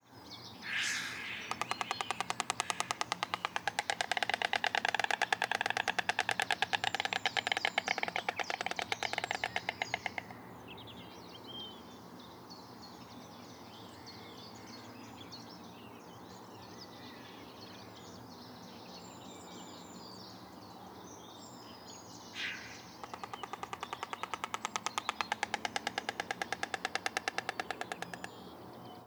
Cegoña branca
Canto
Ciguena-blanca2.wav